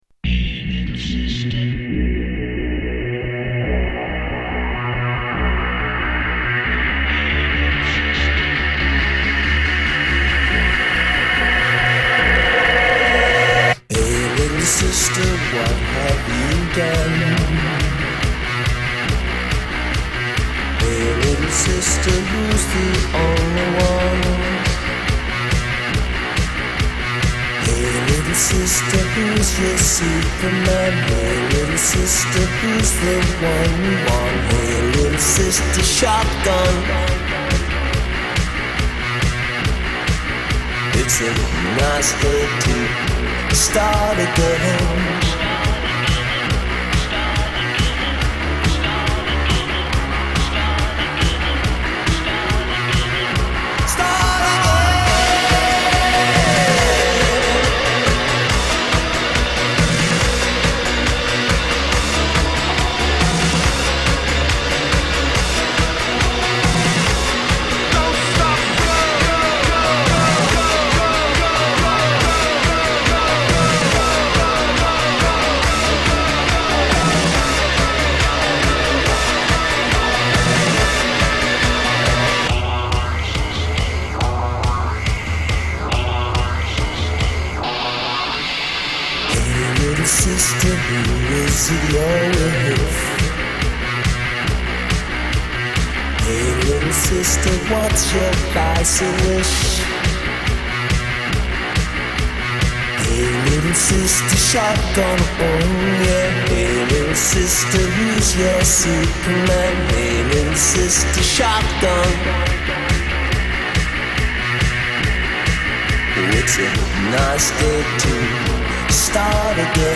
Mash Up songs